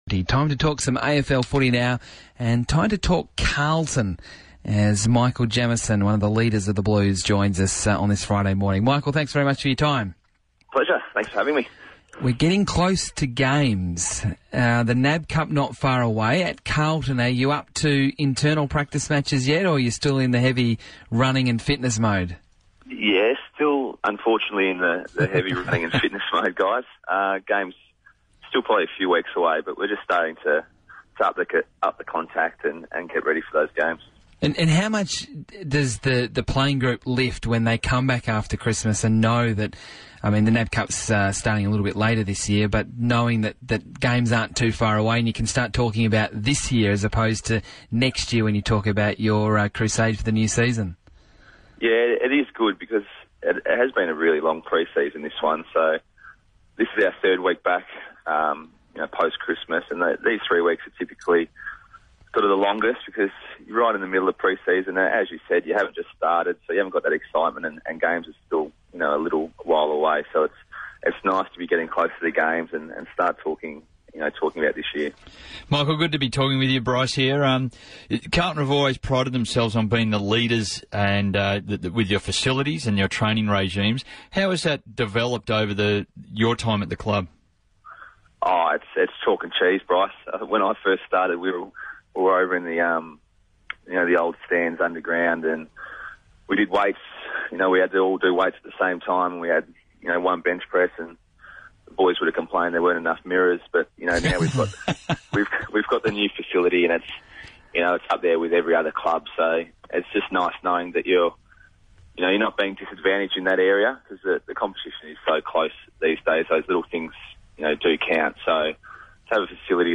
chats to the RSN Breakfast team about Carlton's pre-season campaign, including an update on some of the Club's young stars.